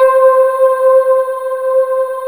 Index of /90_sSampleCDs/USB Soundscan vol.28 - Choir Acoustic & Synth [AKAI] 1CD/Partition D/24-THYLIVOX